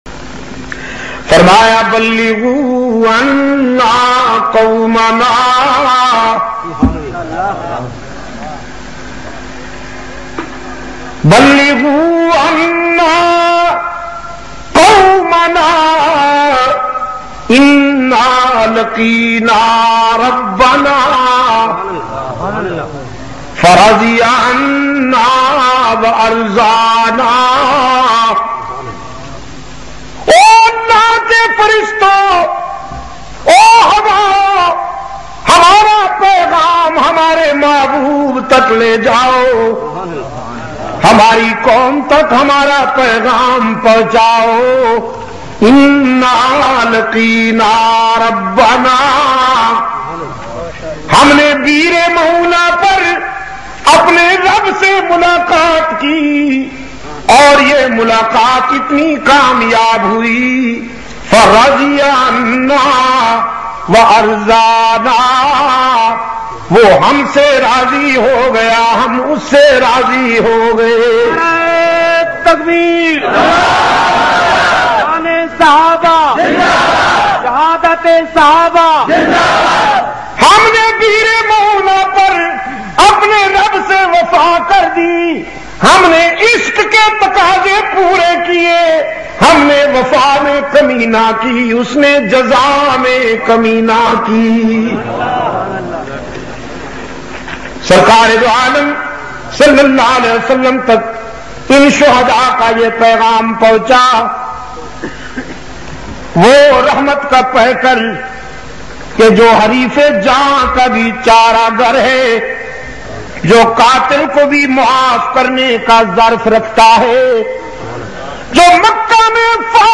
Heart Touching Bayan mp3